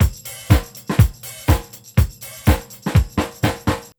INC BEAT2 -L.wav